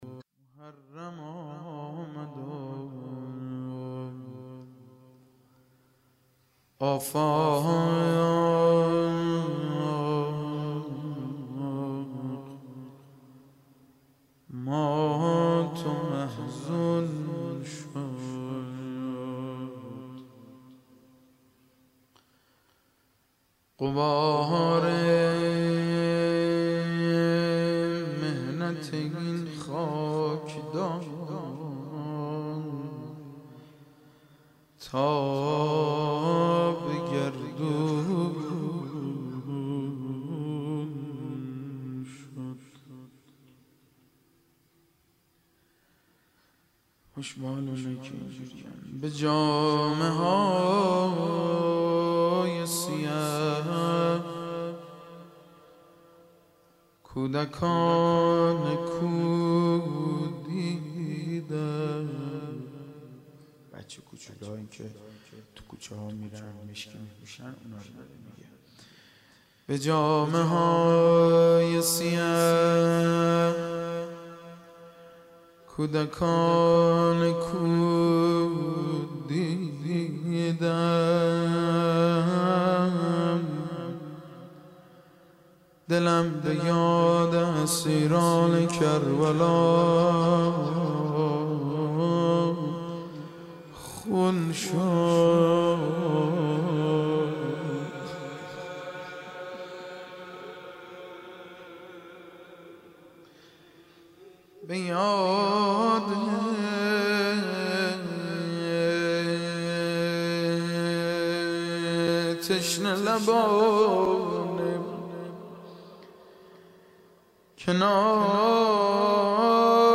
مناسبت : شب دوم محرم
01-Rozeh-1.mp3